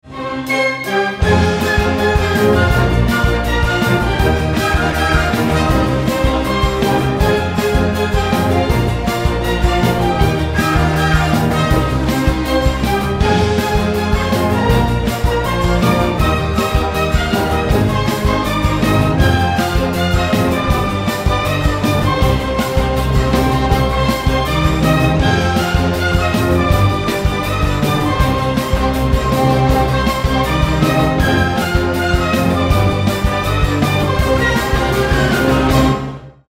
Рингтоны без слов
Инструментальные